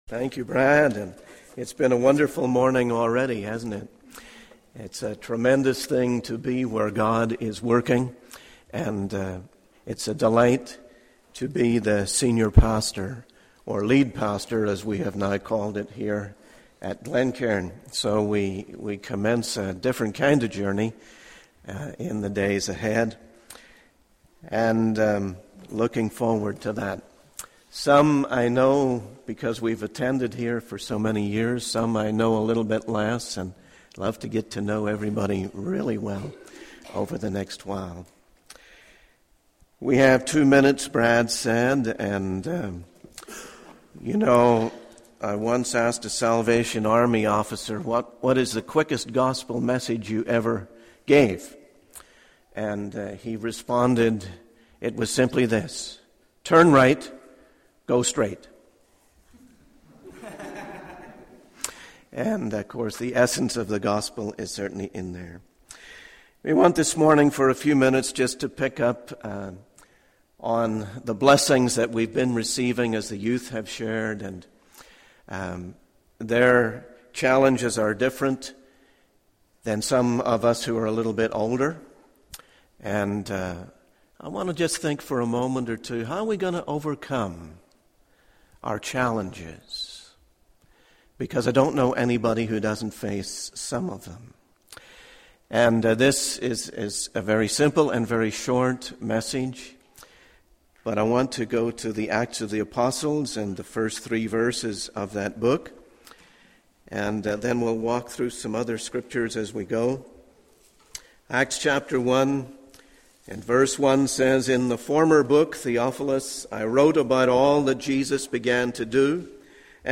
In this sermon, the speaker focuses on the phrase 'through the Holy Spirit' and its significance in the teachings of Jesus.